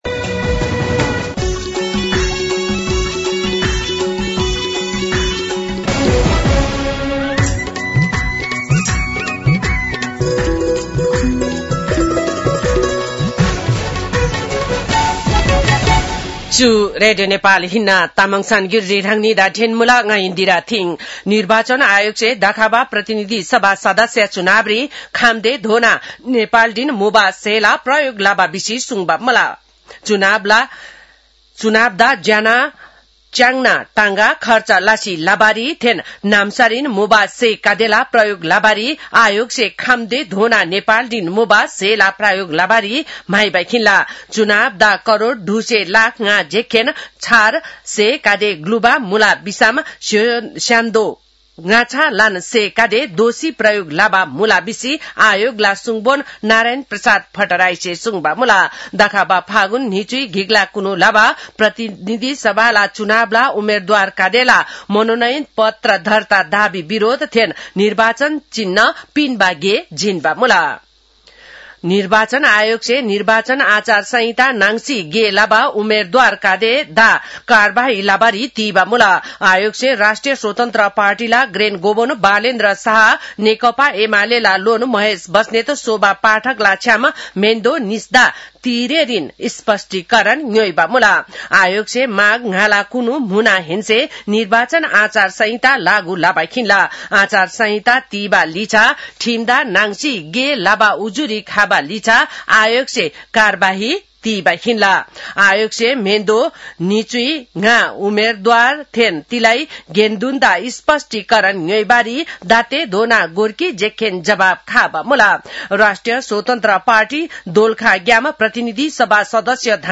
तामाङ भाषाको समाचार : १५ माघ , २०८२